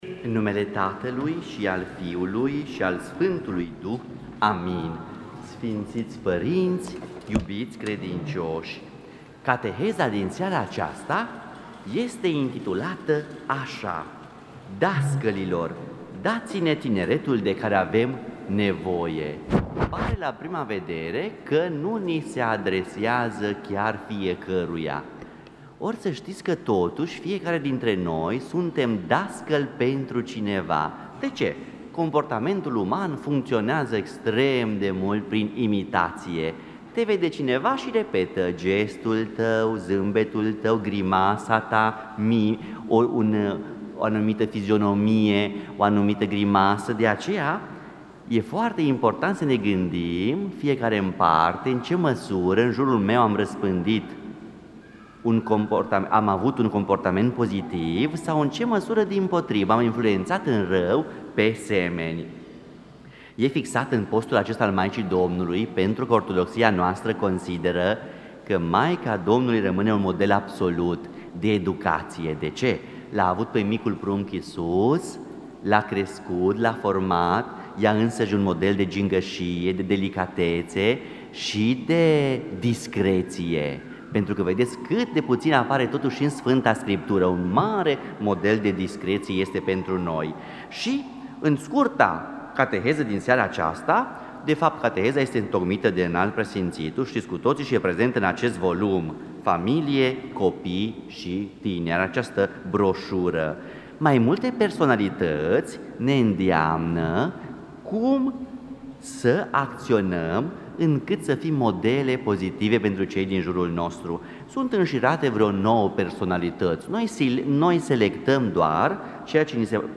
Cuvinte de învățătură Dascălilor, dați-ne tineretul de care avem nevoie!